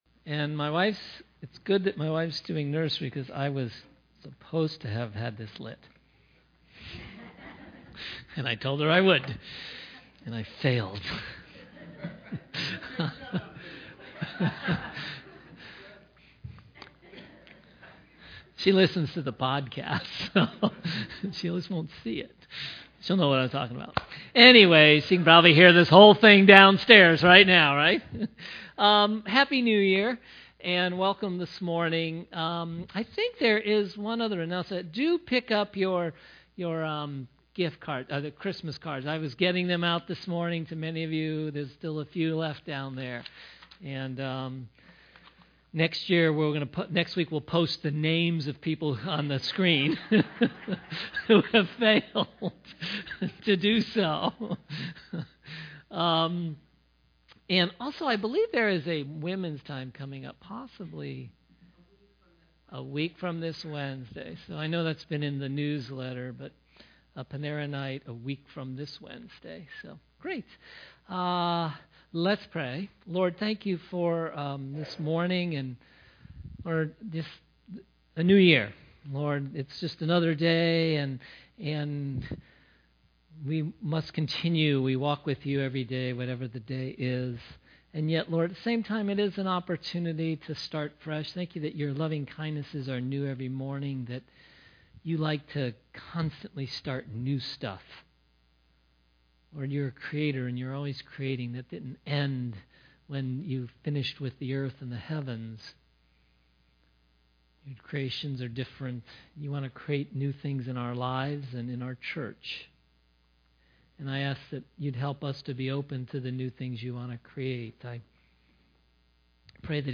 Grace Summit Community Church | Cuyahoga Falls, Ohio
I don’t do New Year’s Resolutions – 97% of them fail… - but even though we don’t believe in them – this is the 2017 Grace Summit New Year’s Resolution sermon.